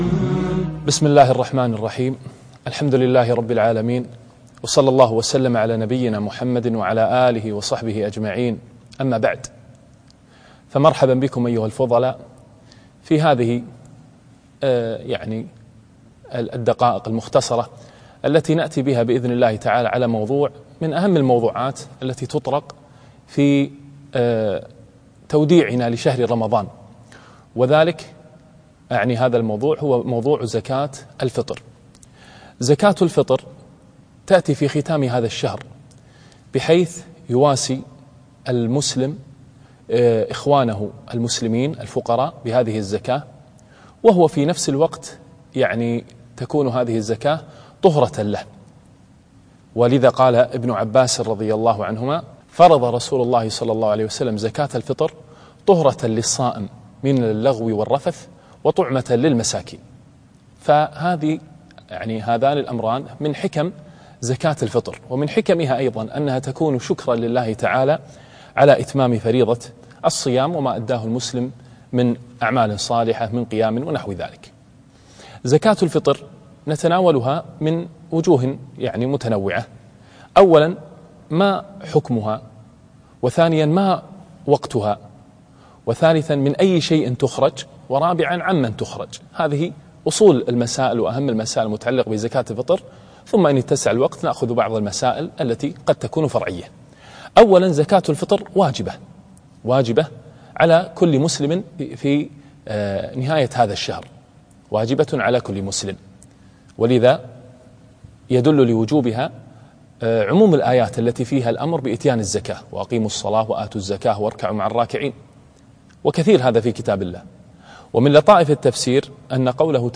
الدروس الرمضانية - زكاة الفطر